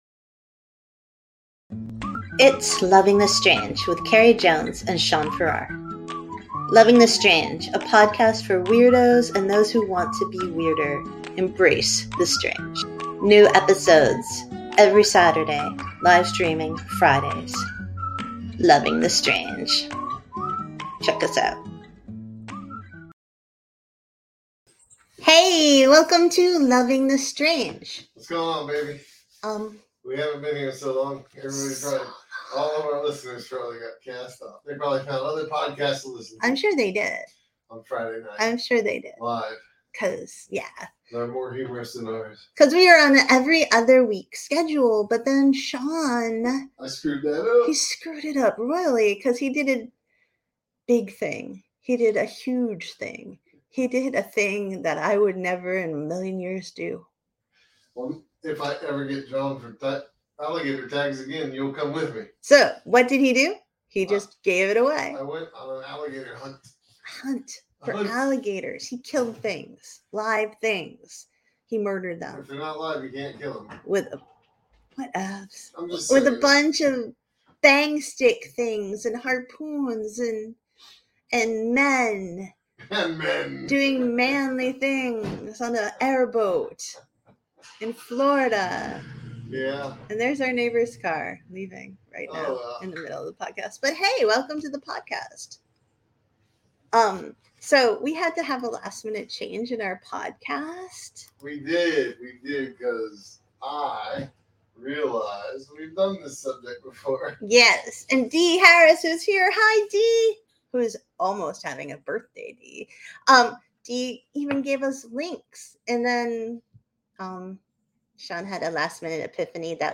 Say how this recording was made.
Random creepy stories - live podcast